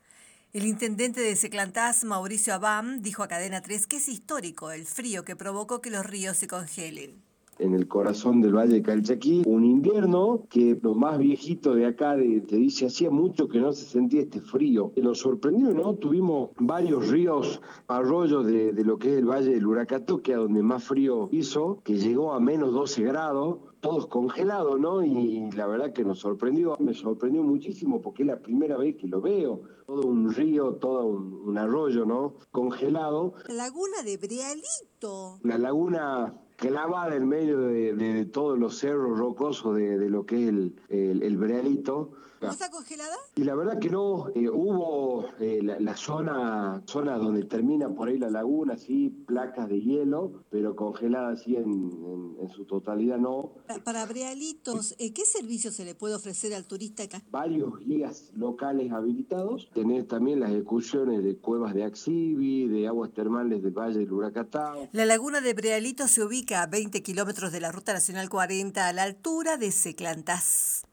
El intendente de Seclantás, Mauricio Abán, compartió su asombro ante este fenómeno con Cadena 3: "Hacía mucho que no se sentía este frío. Tuvimos varios ríos y arroyos todos congelados", contó.